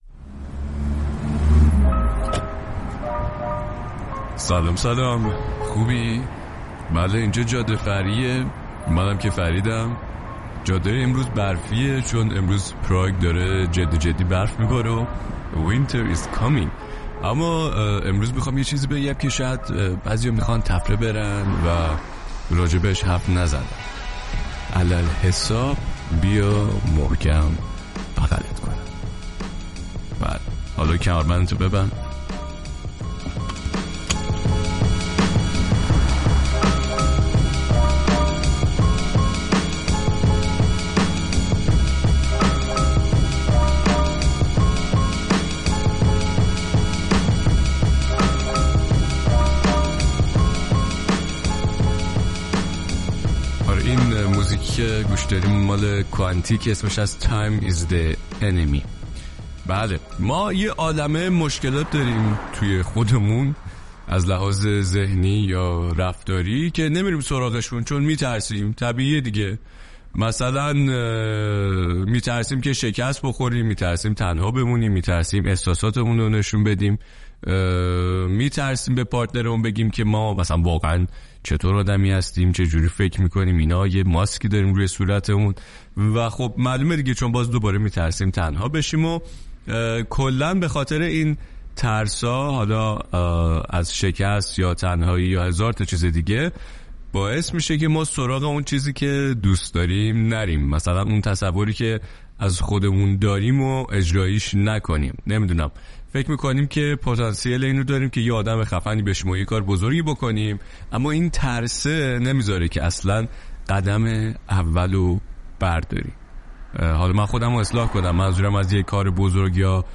جاده فرعی امروز در هوای برفی و فضای سرماخوردگی تهیه شد و حرف حساب اینه که زندگی کوتاهه و هرچه زودتر باید دست به کار شد!